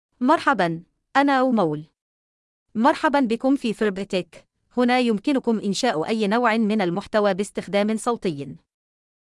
Amal — Female Arabic (Qatar) AI Voice | TTS, Voice Cloning & Video | Verbatik AI
Amal is a female AI voice for Arabic (Qatar).
Voice sample
Listen to Amal's female Arabic voice.
Amal delivers clear pronunciation with authentic Qatar Arabic intonation, making your content sound professionally produced.